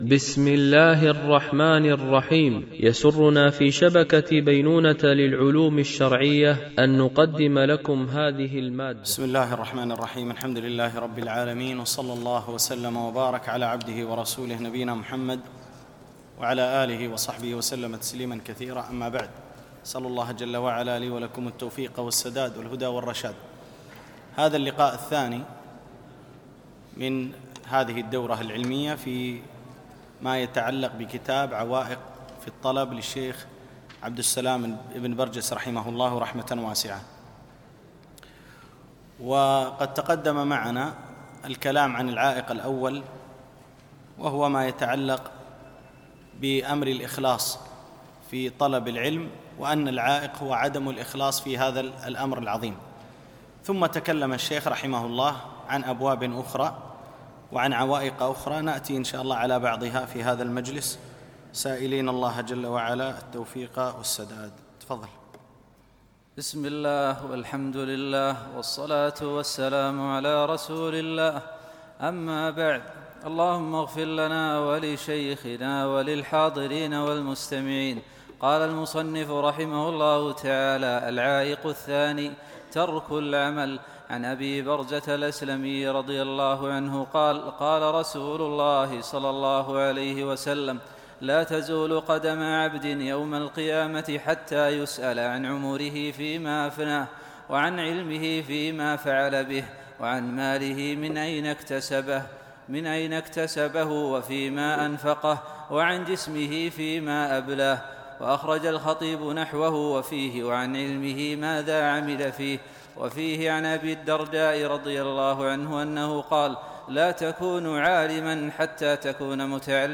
شرح عوائق الطلب ـ الدرس 02